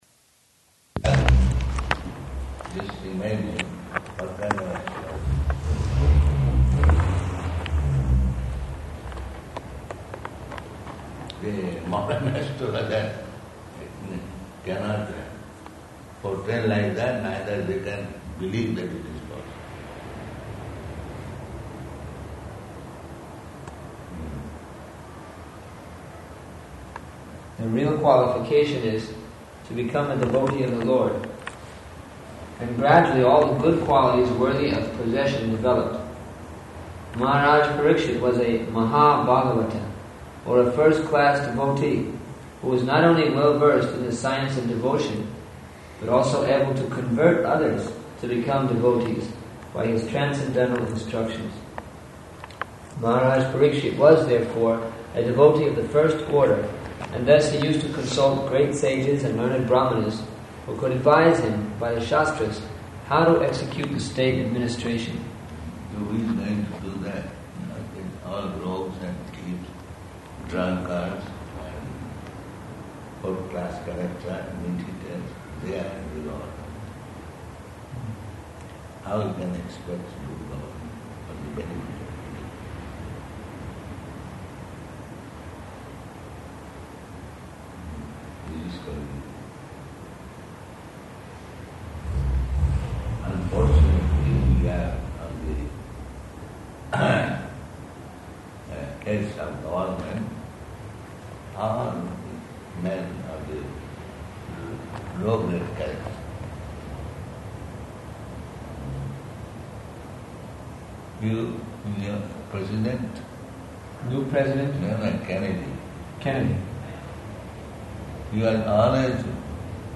Conversation --:-- --:-- Type: Conversation Dated: January 25th 1977 Location: Jagannātha Purī Audio file: 770125R2.PUR.mp3 Prabhupāda: Just imagine what kind of astrologer.